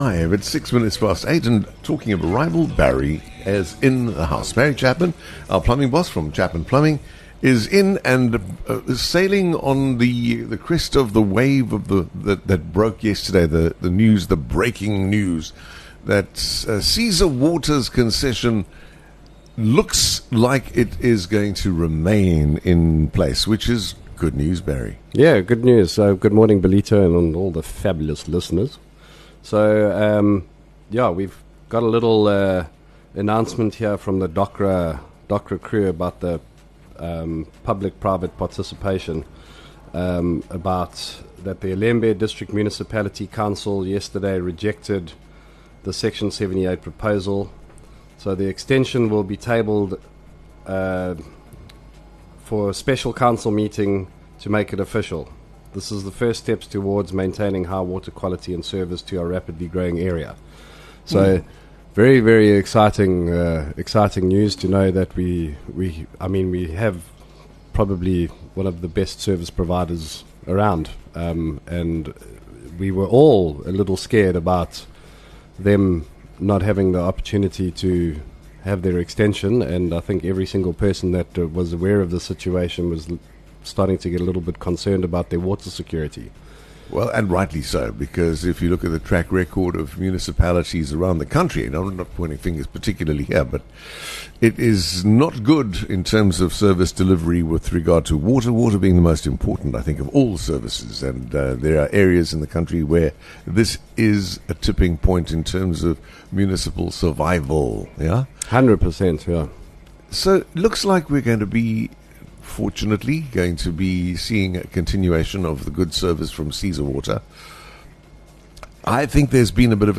Broadcasting live from the heart of Ballito, The Morning Show serves up a curated mix of contemporary music and classic hits from across the decades, alongside interviews with tastemakers & influencers, plus a healthy dose of local news & views from the booming KZN North Coast.